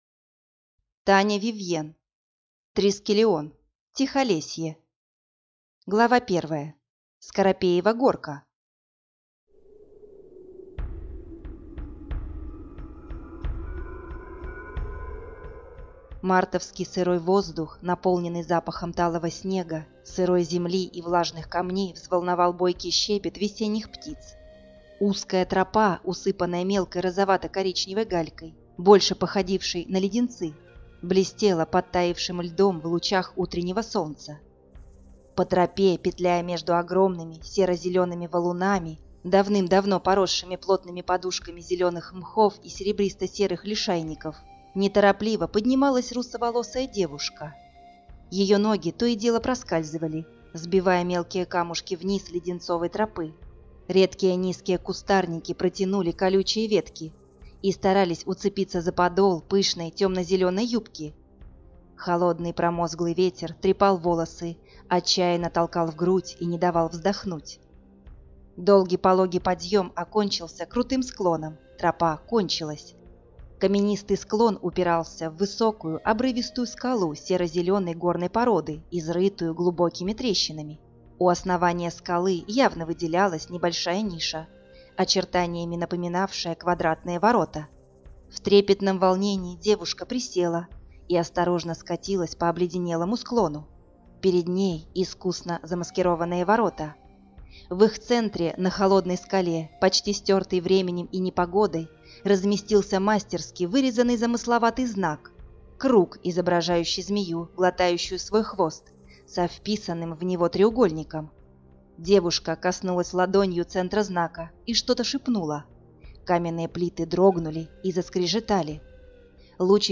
Аудиокнига Трискелион Тихолесье | Библиотека аудиокниг